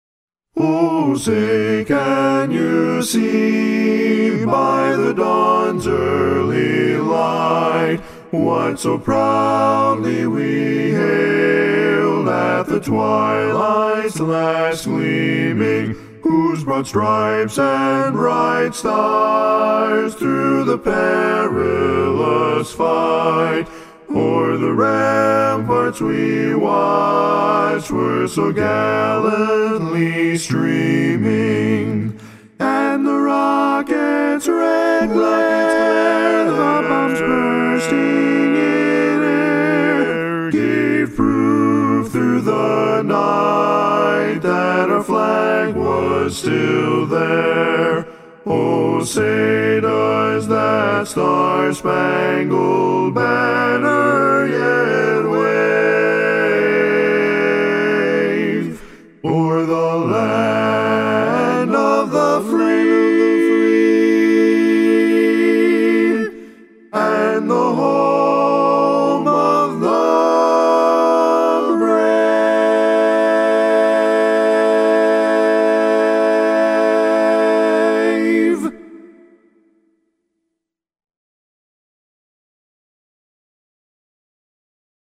Up-tempo
Barbershop
A Major
Full Mix